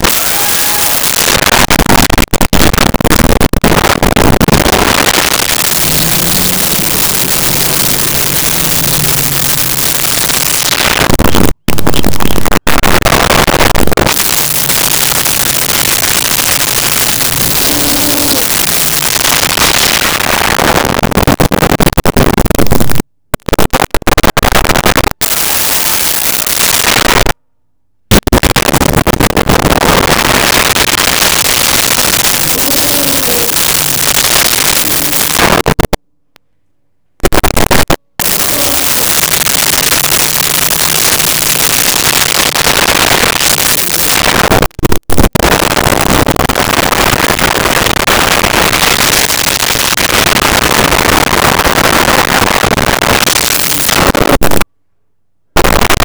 Elephant Trumpets Growls
Elephant Trumpets Growls.wav